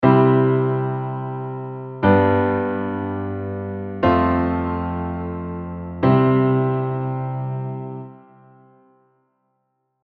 I V IV I becomes tense more quickly and then eases out.